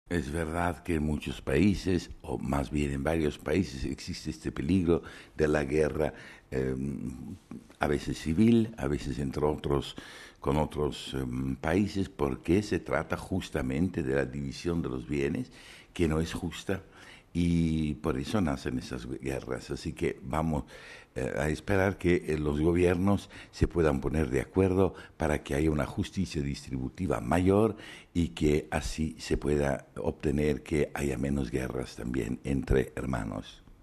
Entrevista al secretario del Pontificio Consejo Cor Unum sobre la decisión de la cancelación de la deuda a varios países en vías de desarrollo